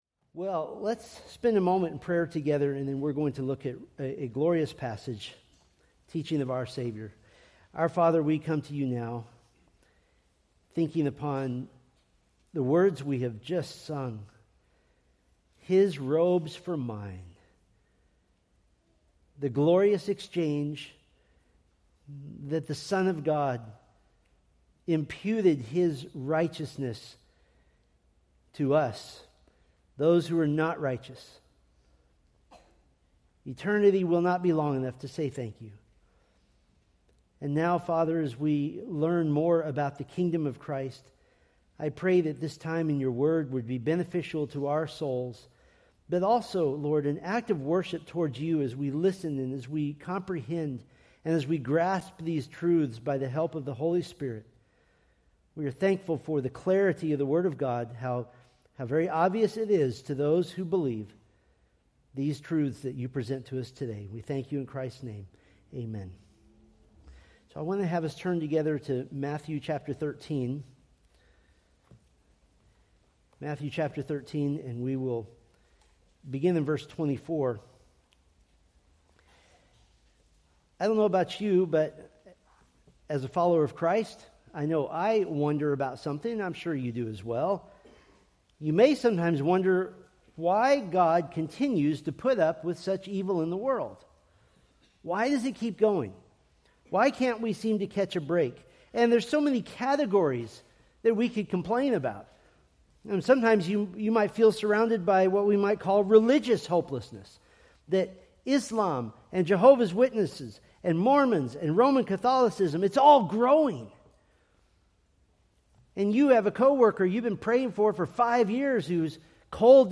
Preached February 15, 2026 from Matthew 13:24-30, 36-44